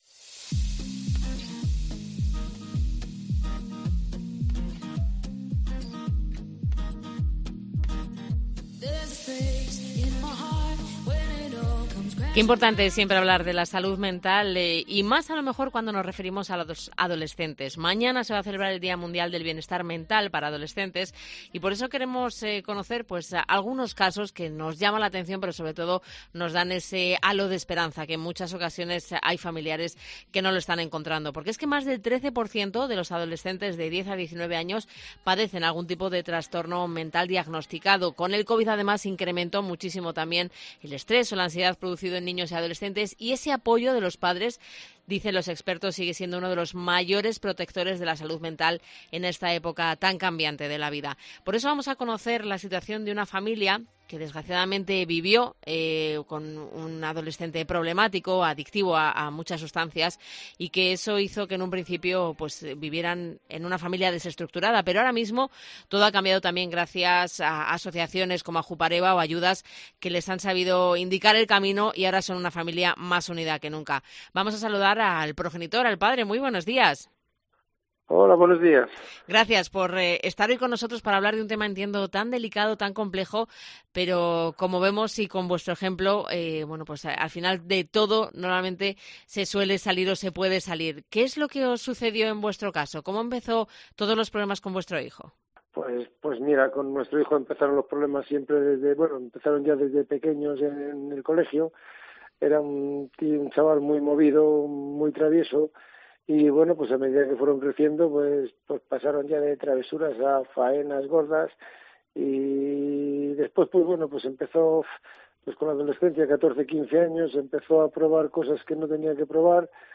Un padre relata en Herrera en COPE cómo salvaron a su hijo de las adicciones